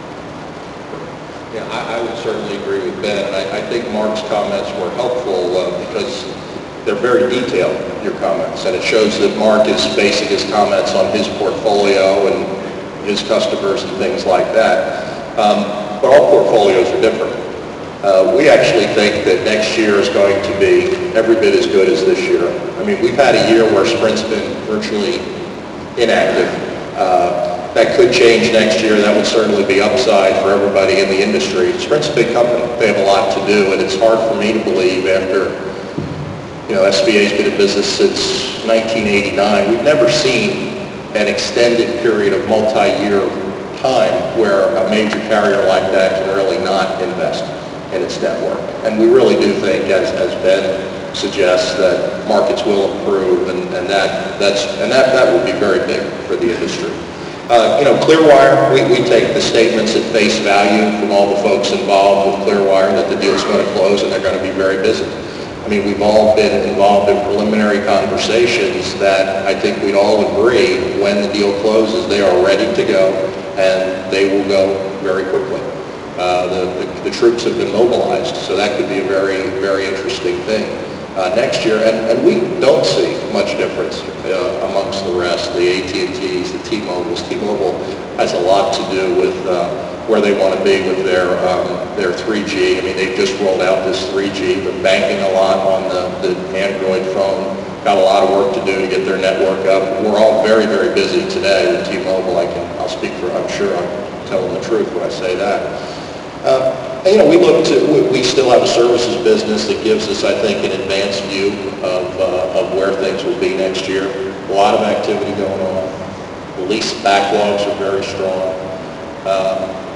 Speaking during the PCIA's final day of The Wireless Infrastructure Show in Hollywood, FL yesterday, the titans of towers were enthusiastic about lease-up opportunities for the coming year.